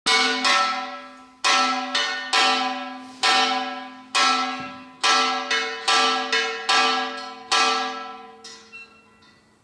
01ramsach_glocke_test.wav